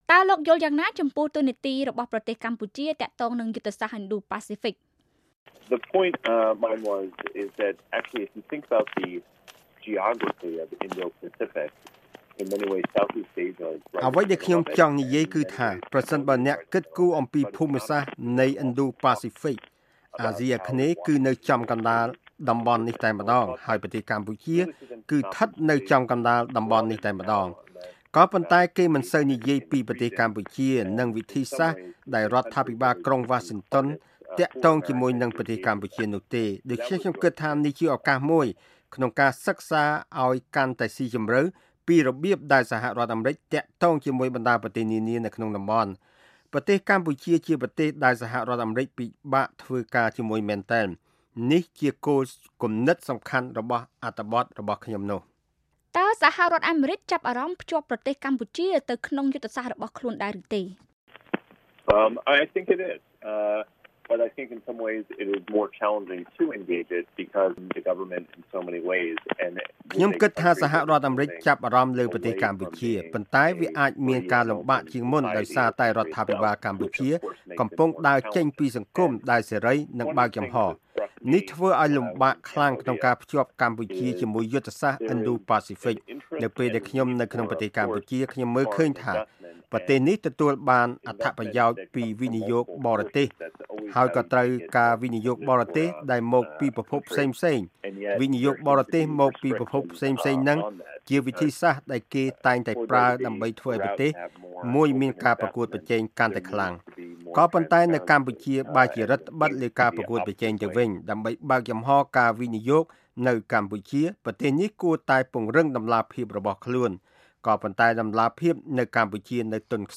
បទសម្ភាសន៍ VOA៖ ការពឹងផ្អែកលើម្ចាស់បំណុលតែមួយធ្វើឲ្យពលរដ្ឋខ្មែរមានកំហឹង